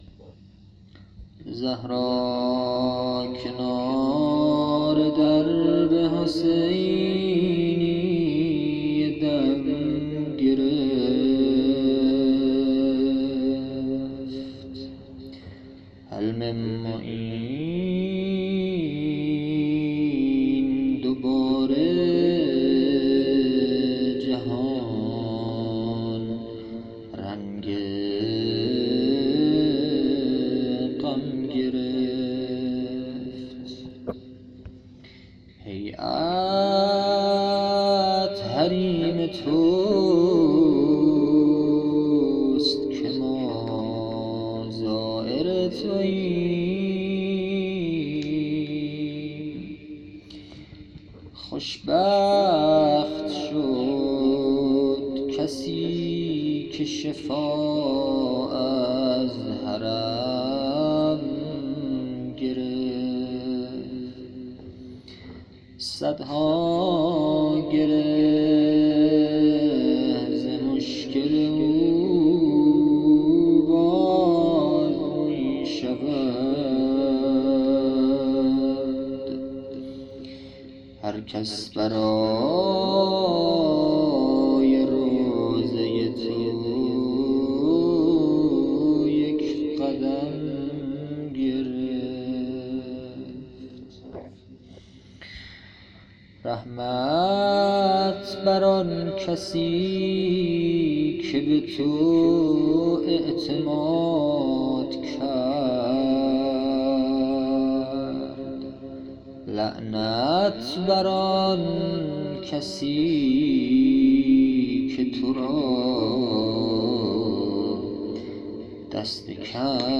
محرم 1400
مناجات